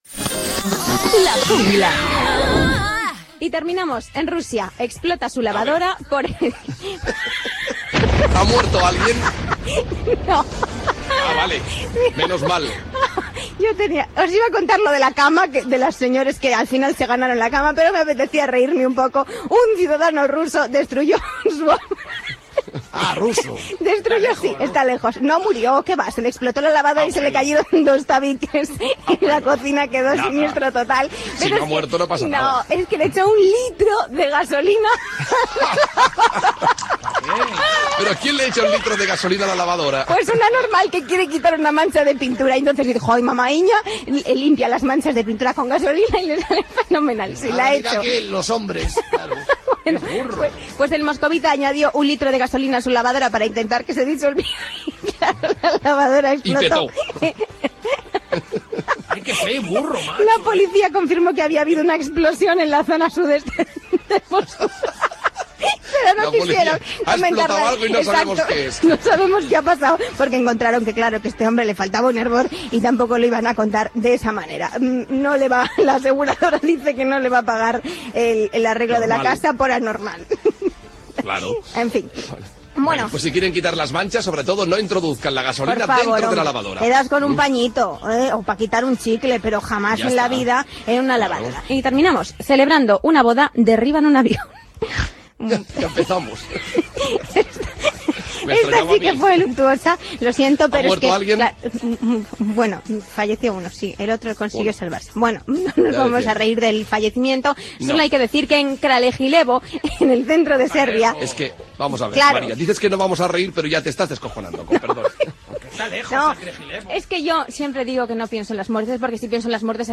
Indicatiu del programa i resum de notícies curioses
Entreteniment
FM